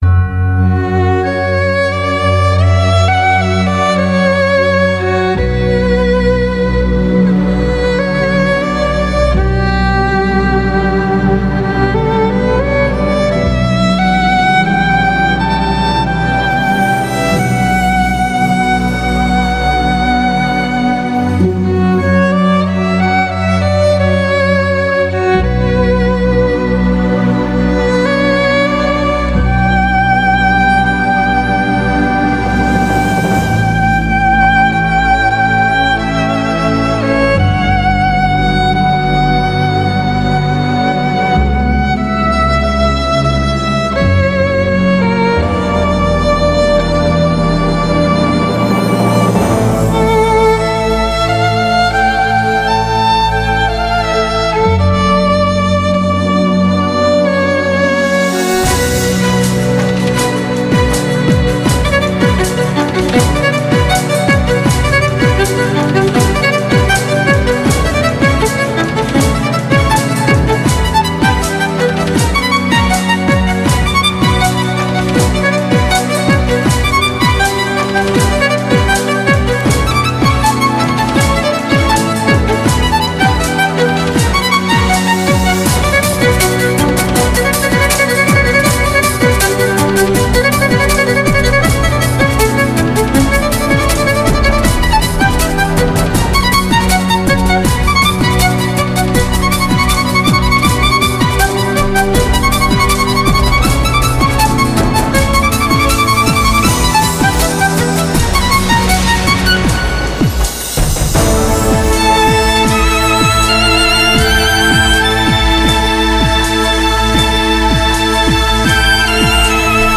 ЦІКАВА "ІКЕБАНА" ІЗ СТРУННИХ МУЗИЧНИХ ІНСТРУМЕНТІВ.
красива музика Вівальді - печальна
ПРИЄМНО, ЩО НЕ ТІЛЬКИ Я ВІДЧУЛА БОЖЕСТВЕНУ КРАСУ ЦІЄЇ МУЗИКИ - СПОЧАТКУ НІБИ ТИША І СПОКІЙ, А ПОТІМ ШКВАЛ ПОЧУТТІВ І ЕМОЦІЙ!!!